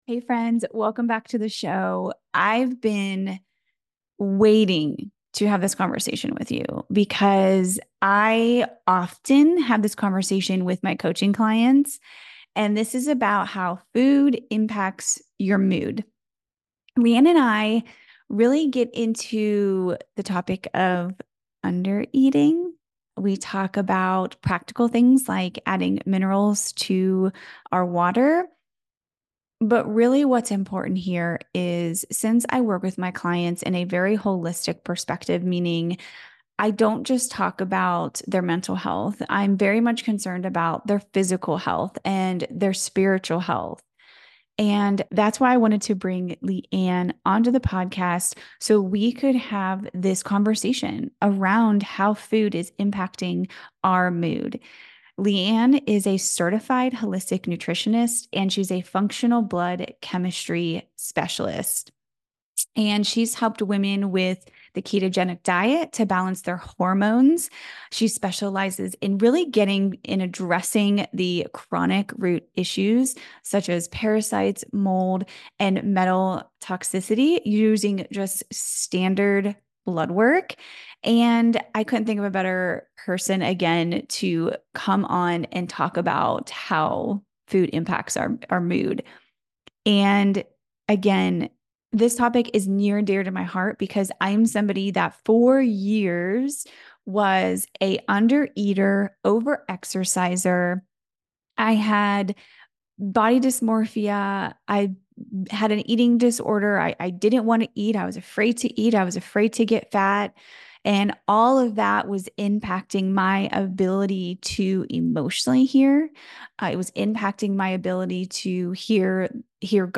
Our conversation includes the prevalence of under-eating, how nutritional deficiencies affect our mental health, the impact chronic stress can have on adrenal function and cortisol production, and the emotional challenges tied to eating. We also offer some practical steps to increase caloric intake and discuss how mineral supplementation can help get your system back in balance.